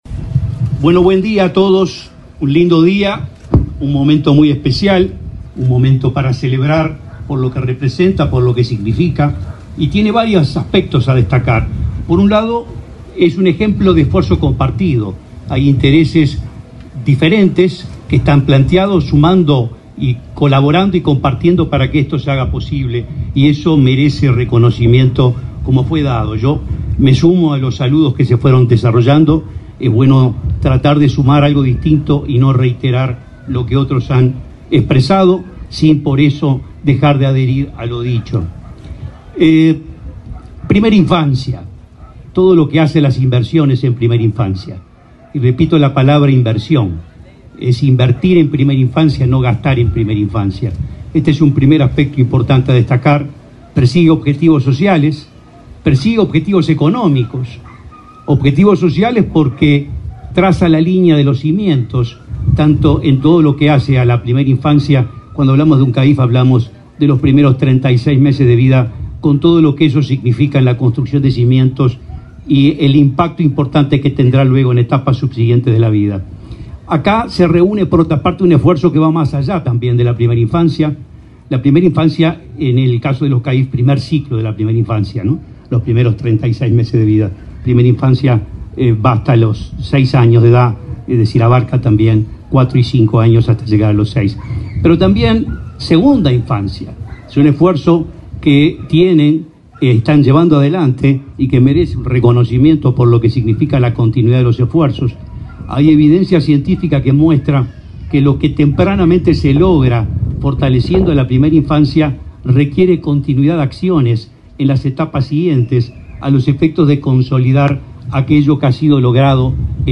Palabras de autoridades en acto del Mides en Canelones
Palabras de autoridades en acto del Mides en Canelones 24/09/2024 Compartir Facebook X Copiar enlace WhatsApp LinkedIn Este martes 24 en Las Piedras, Canelones, el presidente del Instituto del Niño y el Adolescente del Uruguay (INAU), Guillermo Fosatti, y el ministro de Desarrollo Social, Alejandro Sciarra, participaron, en la inauguración de un centro Siempre, un servicio socioeducativo dirigido a la infancia, sus familiares y la comunidad.